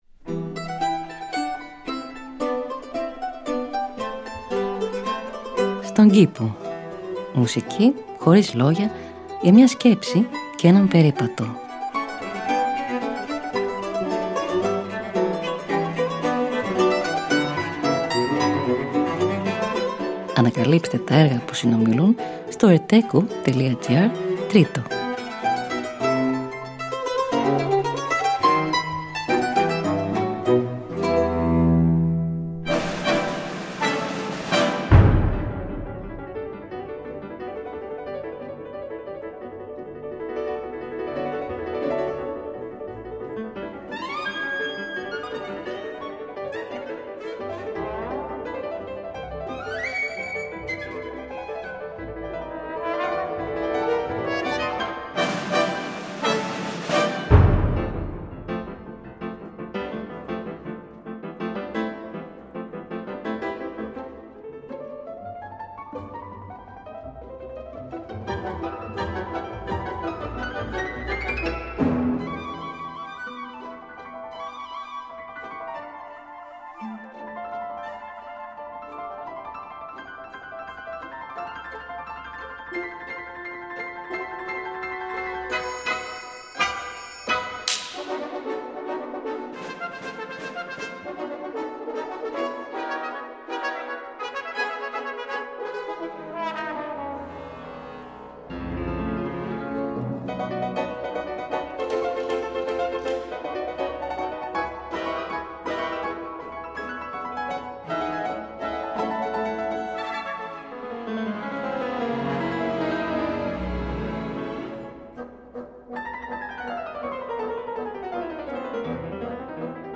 Μουσική Χωρίς Λόγια για μια Σκέψη και έναν Περίπατο.
Allegro – Arrange for mandolin and continuo: Avi Avital